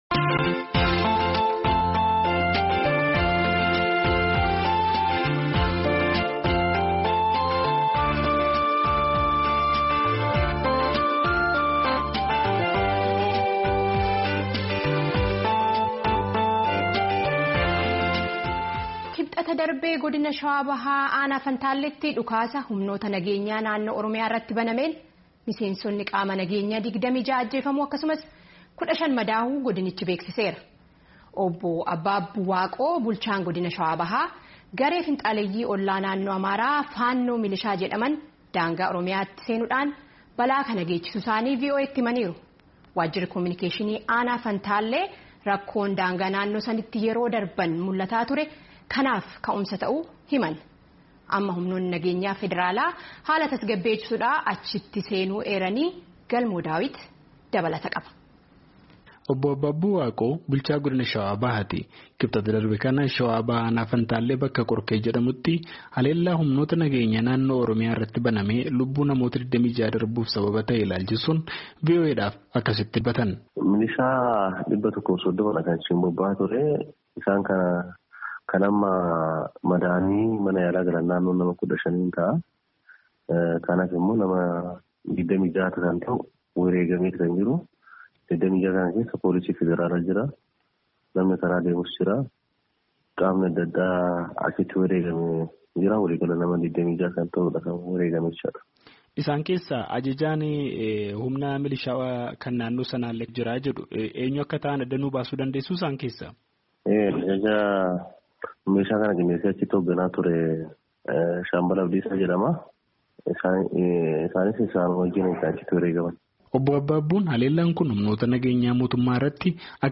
Ajjeechaa Qaama Nageenya Fantaallee Ilaalchisee Gabaasa Dhiyaate Caqasaa